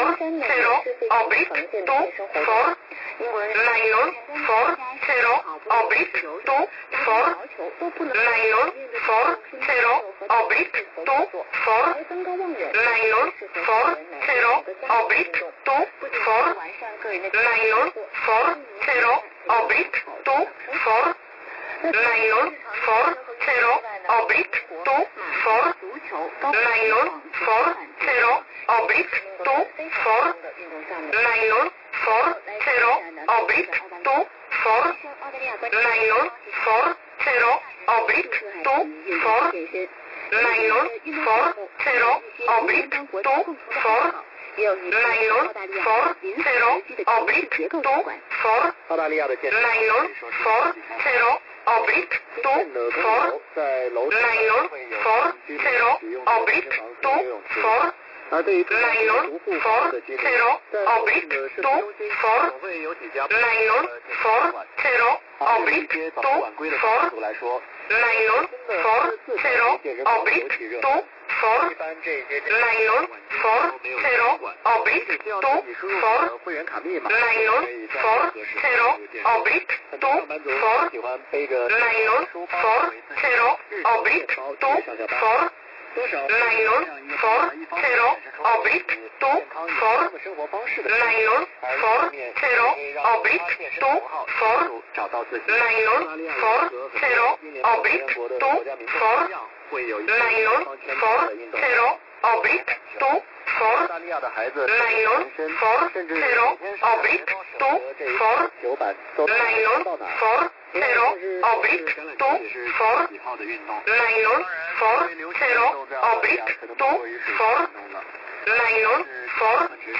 15800 khz